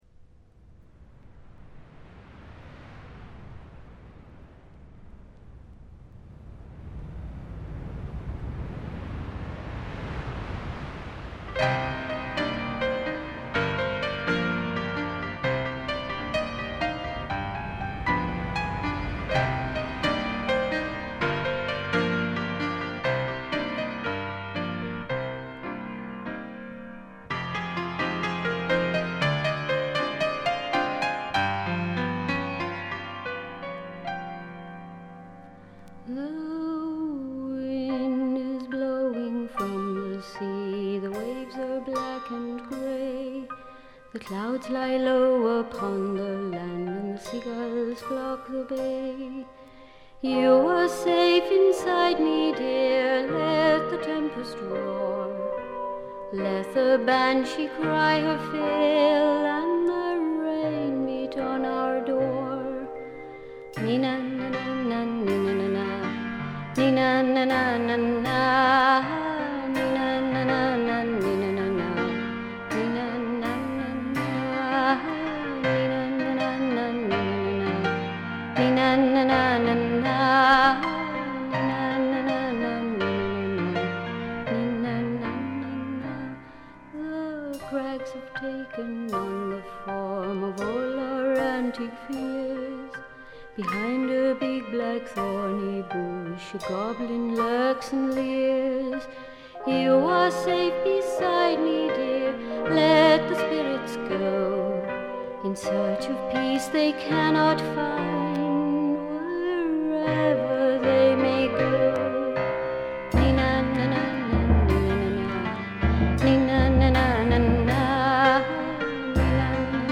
A5で軽微なチリプチ少し。
メランコリックな曲が多く彼女のヴォーカルは情感を巧みにコントロールする実に素晴らしいもの。
試聴曲は現品からの取り込み音源です。
Vocals, Harp [Irish]
Recorded & mixed At Hollywood Studios, Rome, April 1983.